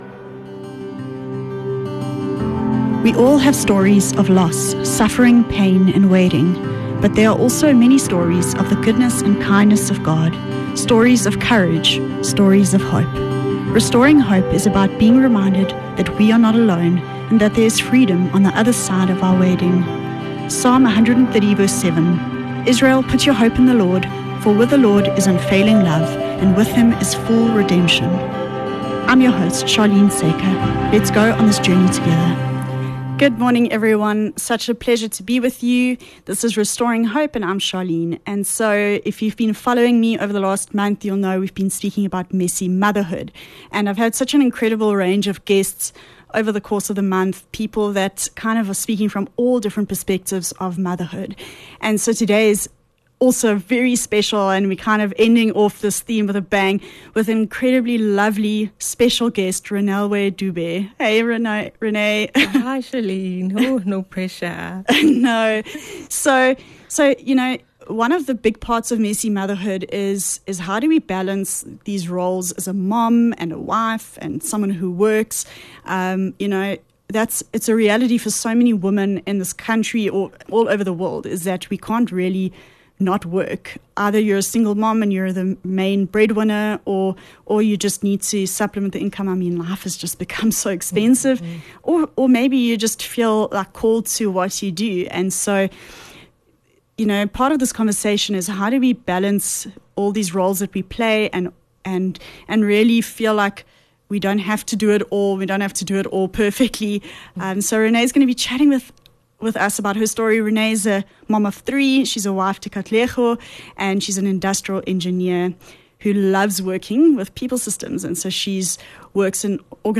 27 Mar Messy Motherhood - Interview